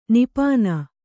♪ nipāna